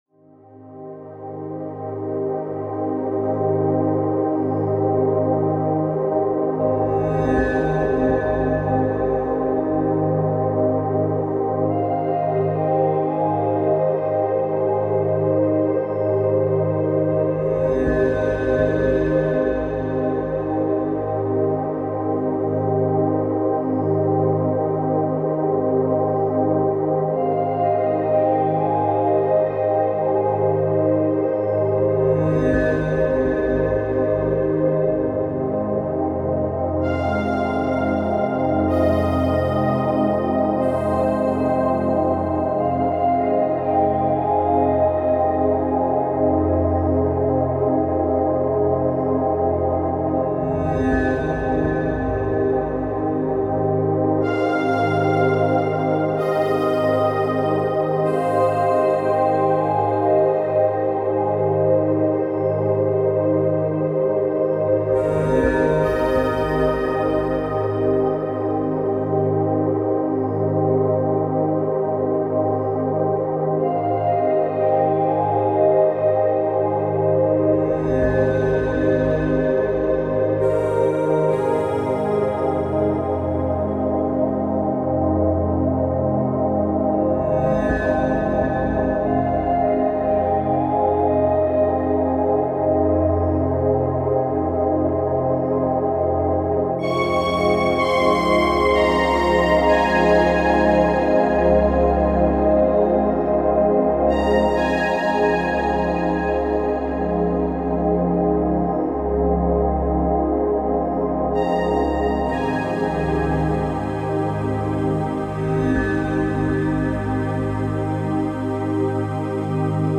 Sphärisch wabernd